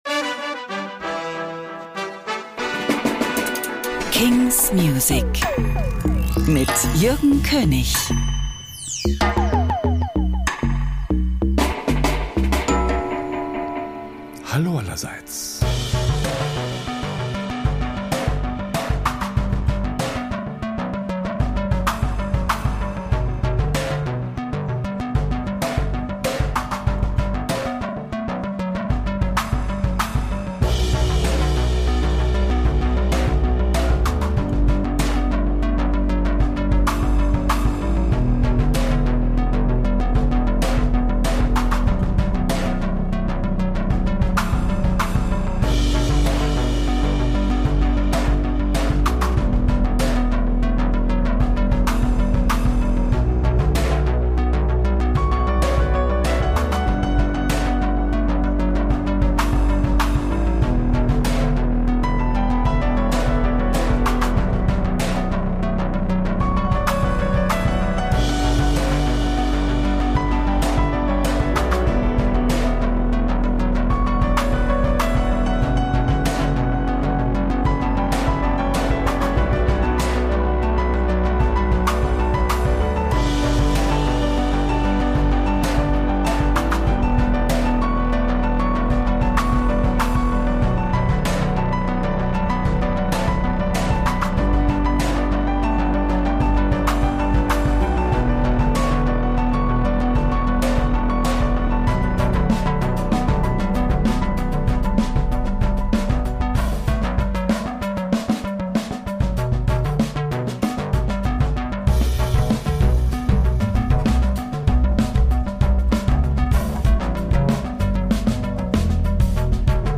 indie & alternative